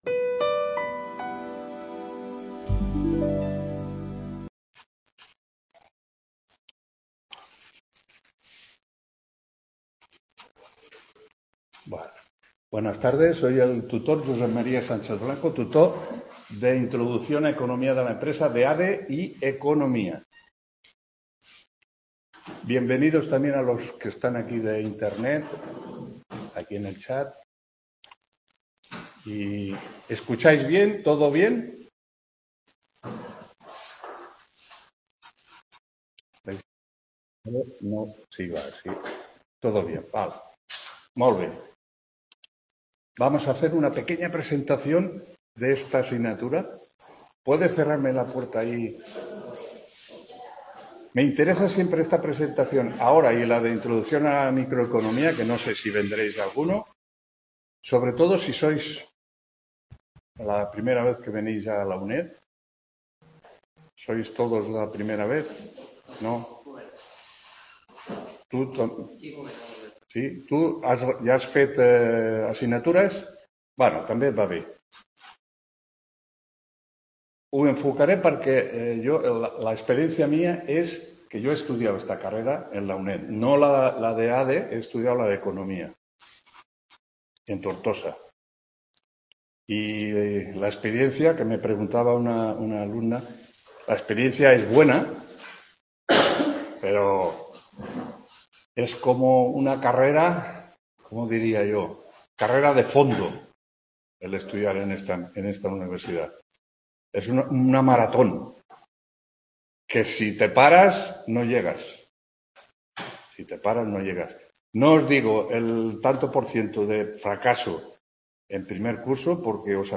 1ª TUTORÍA INTRODUCCION A LA ECONOMÍA DE LA EMPRESA 17…